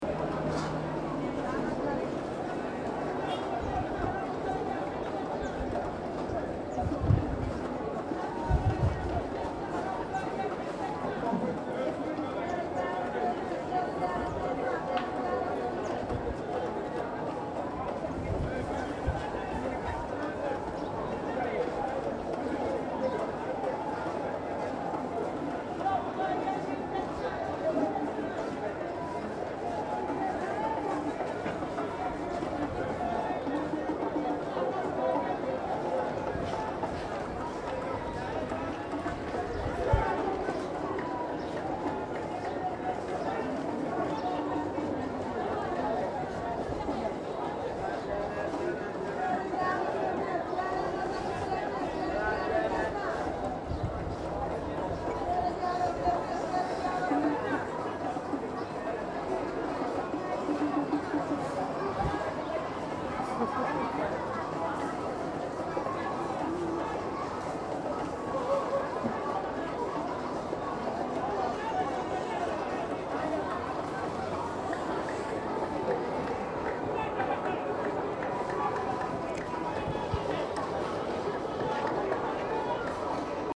10/05/2014 18:00 Au marché Tilène, on vend de tout, mais ça sent surtout le poisson.
Sur un bon kilomètre, les femmes négocient ferme le prix du yard de wax, sans prêter attention à la circulation des camions et aux joueurs de sabars. C'est la fin de la journée, le soleil décline, et nos forces aussi.